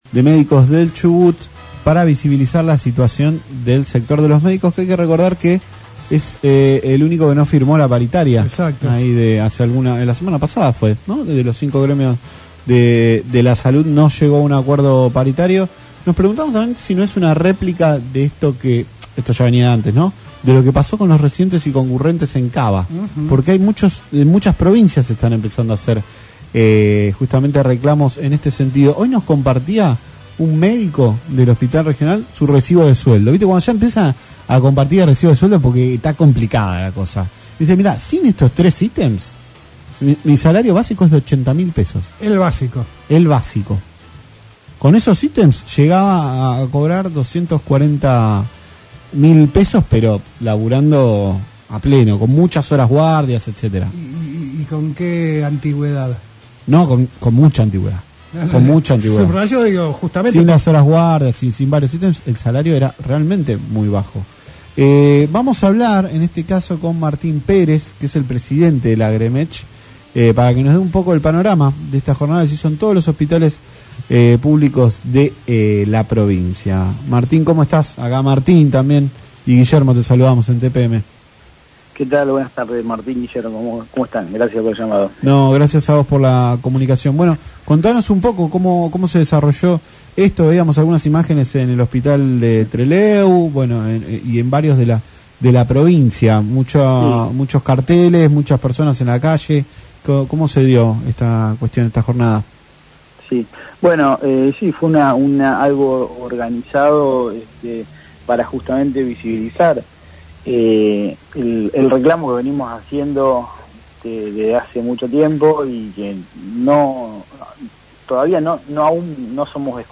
En diálogo con LaCienPuntoUno